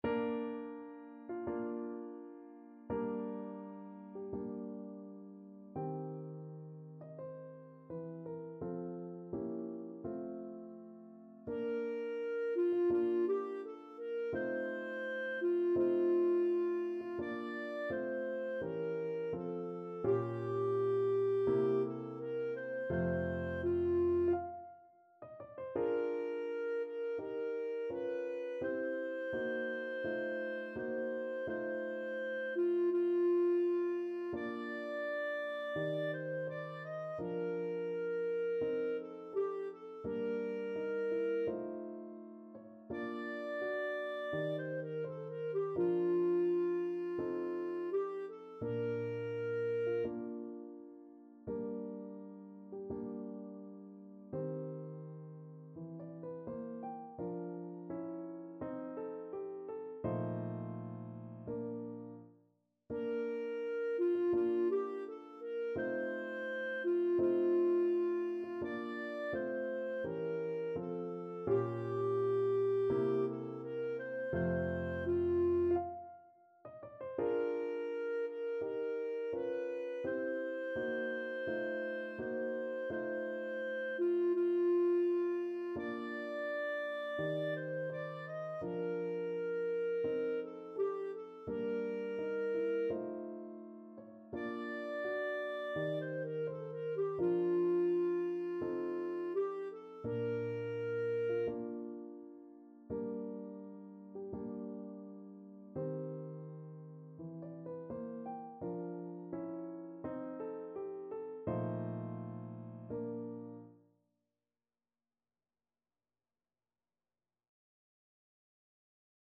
Classical Schubert, Franz An die Sonne, D.270 Clarinet version
2/4 (View more 2/4 Music)
Bb major (Sounding Pitch) C major (Clarinet in Bb) (View more Bb major Music for Clarinet )
~ = 42 Sehr langsam
Classical (View more Classical Clarinet Music)